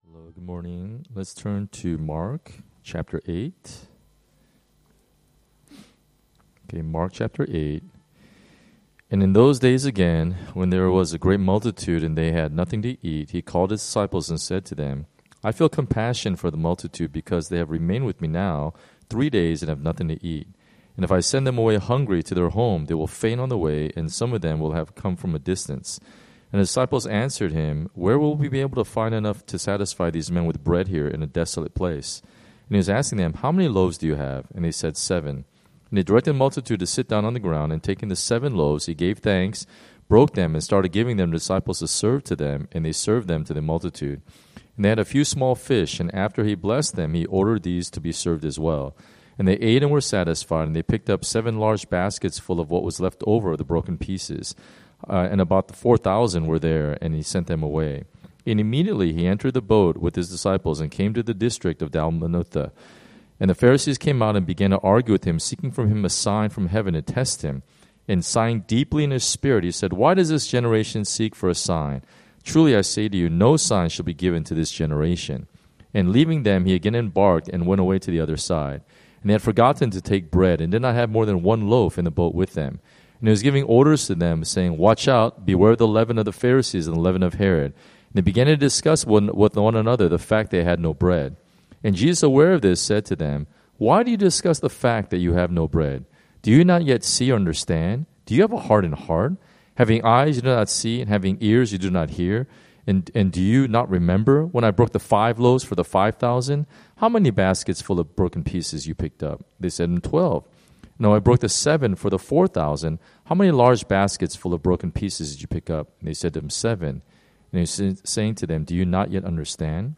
Early Morning Prayer devotionals from Solomon's Porch Hong Kong.